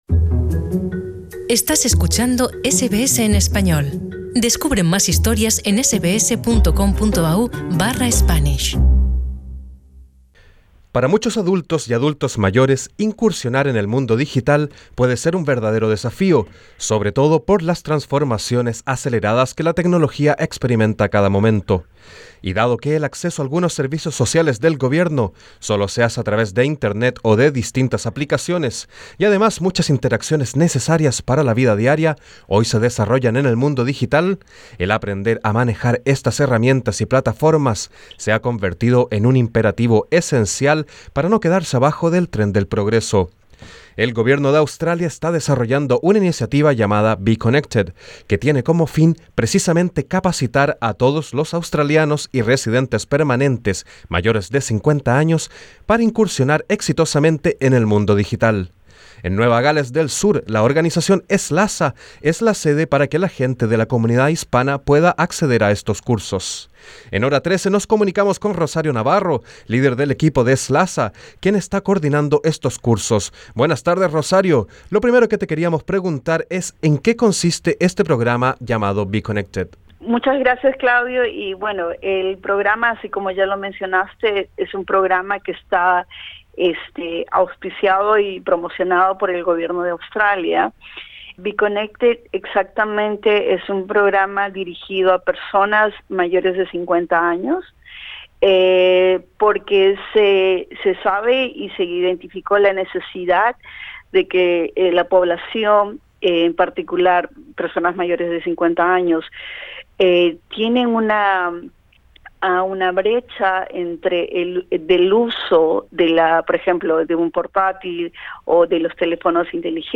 En Hora 13 entrevistamos